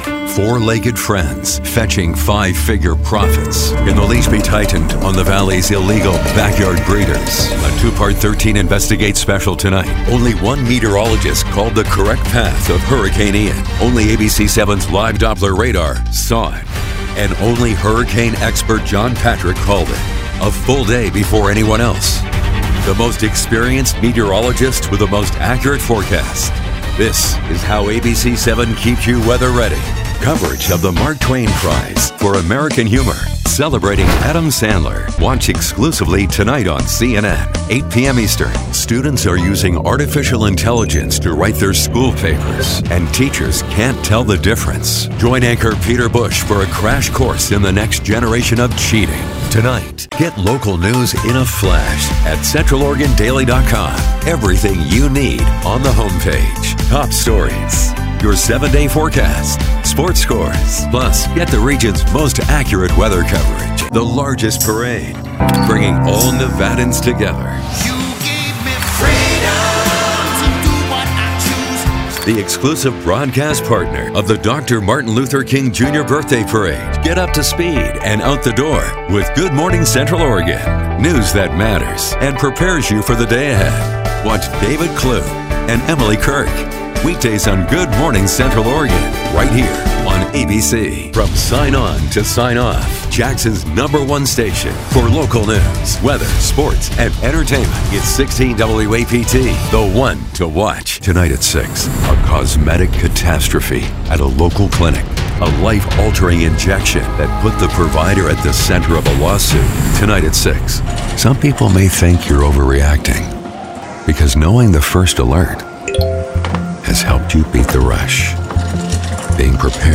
Imaging Voice Demos: